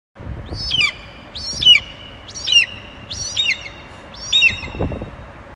Aquila di Bonelli
AQUILA-Di-BONELLI-Aquila-fasciata.mp3